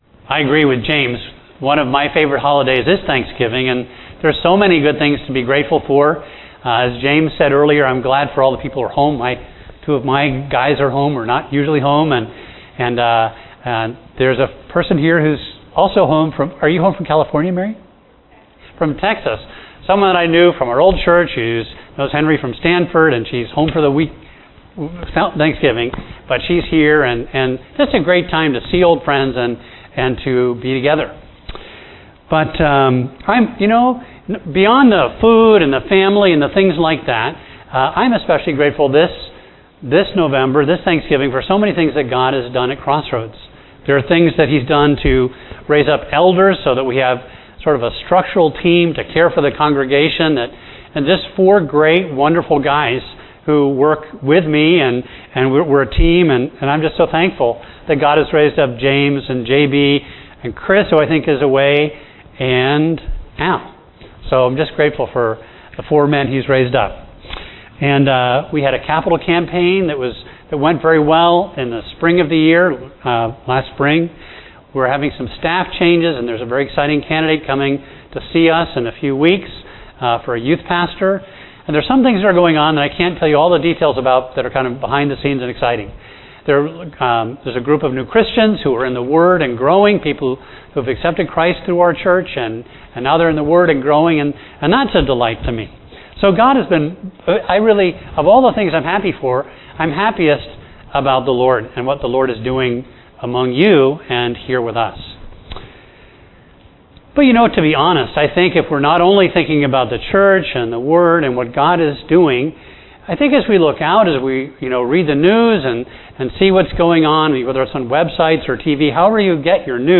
A message from the series "The Acts."